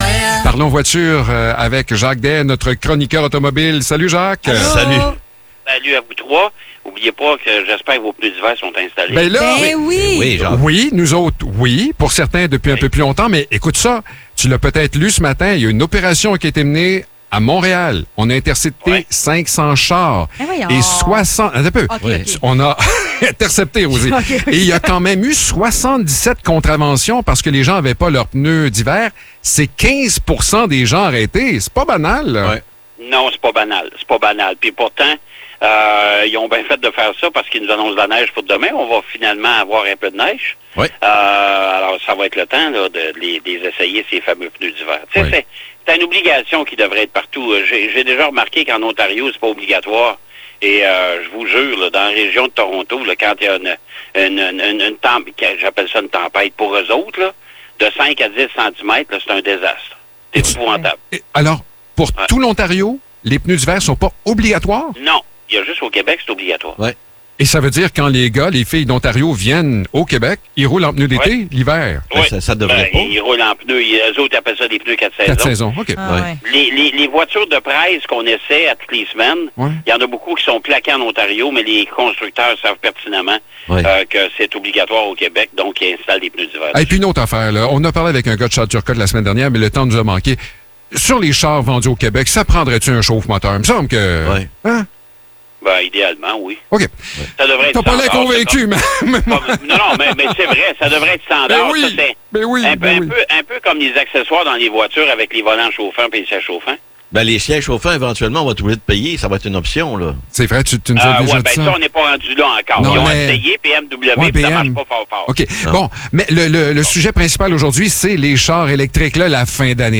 Chronique automobile